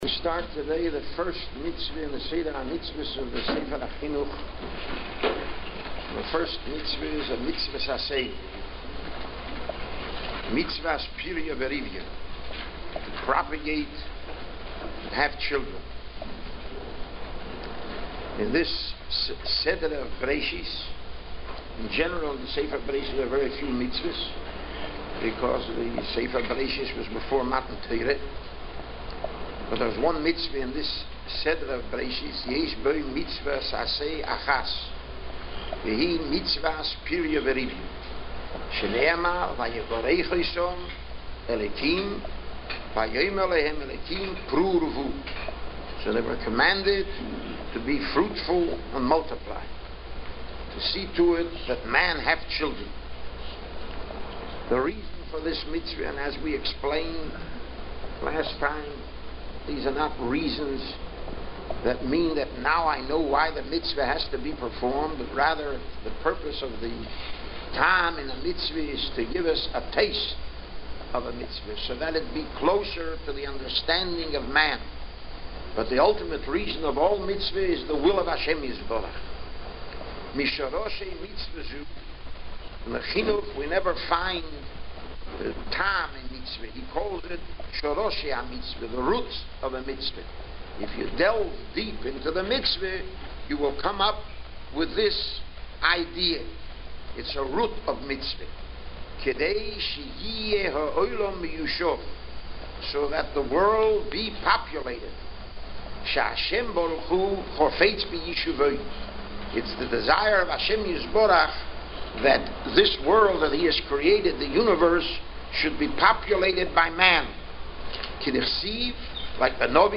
giving a shiur on Minchas Chinuch on the first Mitzvah.